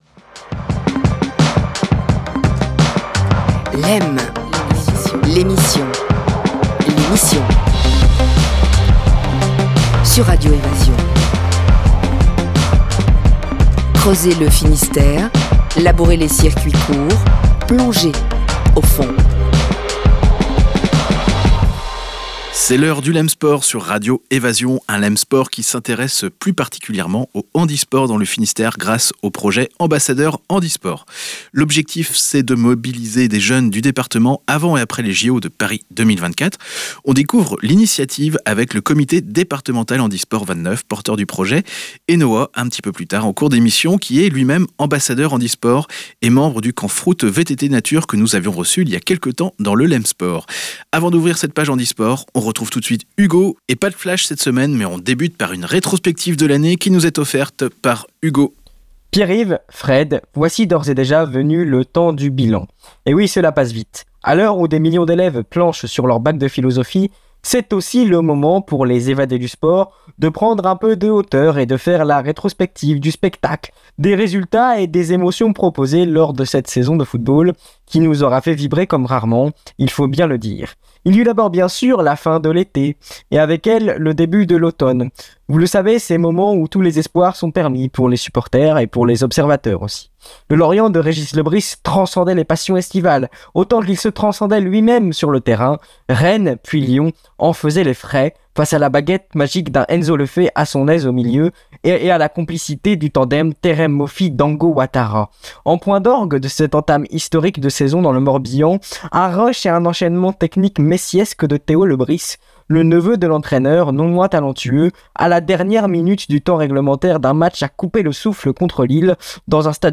Nous vous proposons une interview réalisée par les jeunes du service info jeunesse à Plougastel.